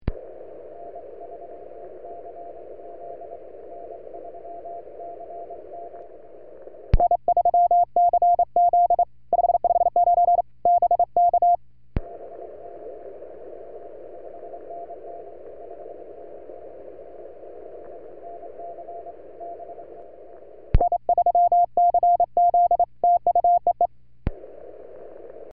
30MTR CW